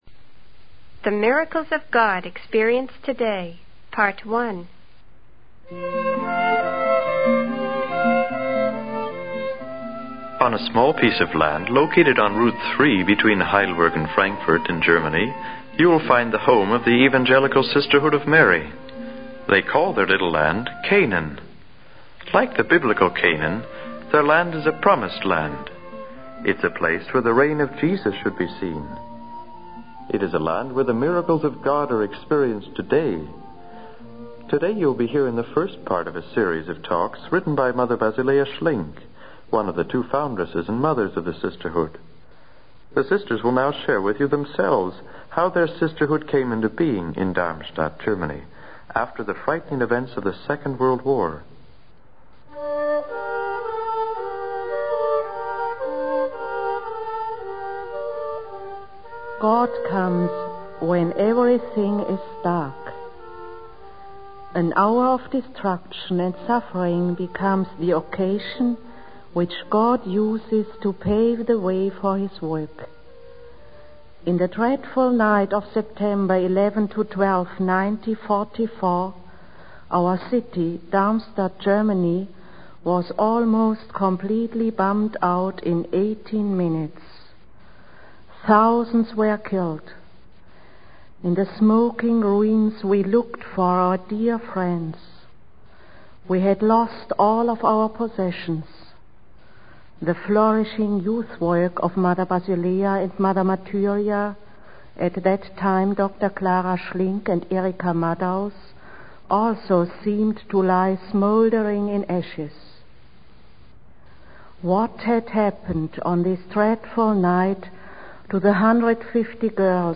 Messages) Nearness of Jesus